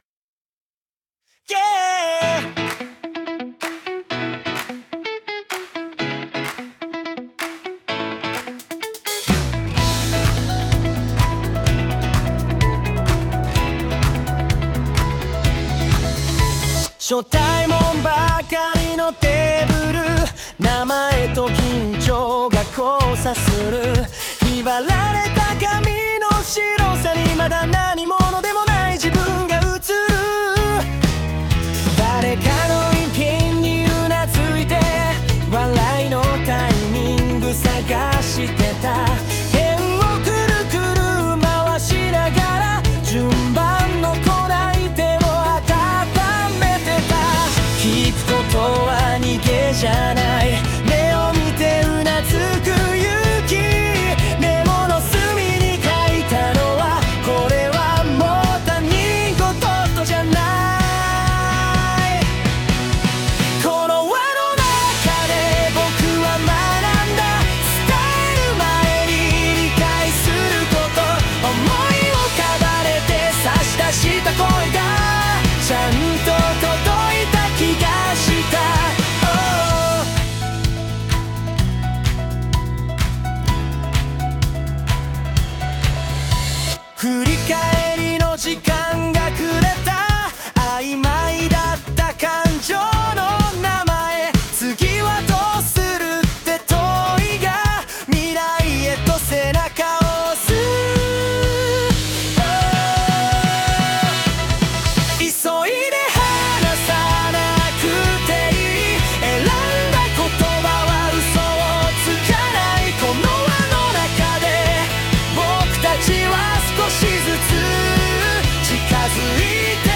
「他者への敬意」と「合意形成」を経て生まれた歌詞を、最新のAI技術（SUNO）を活用して楽曲にしました。
楽曲コンセプト： 初めて出会った時の緊張から、対話を通じて「自分の居場所」を見つけるまでの「心の機微」を描いたバラード。